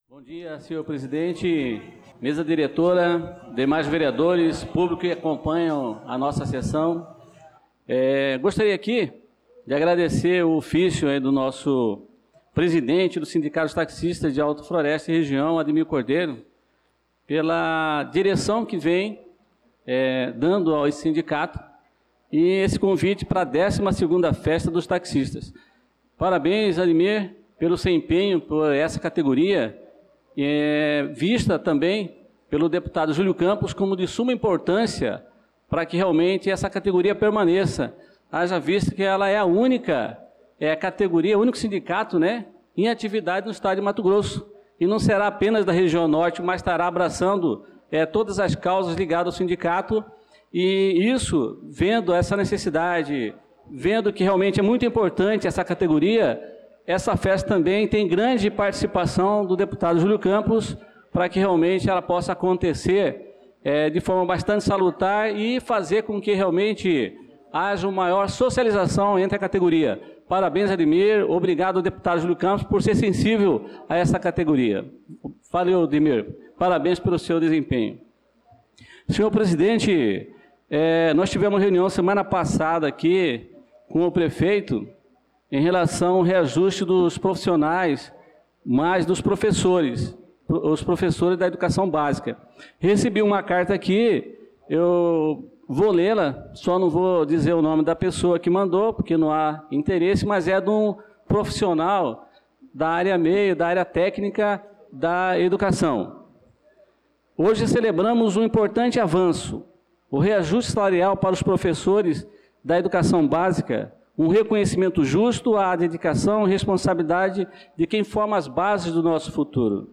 Pronunciamento do vereador Adelson Servidor na Sessão Ordinária do dia 07/07/2025.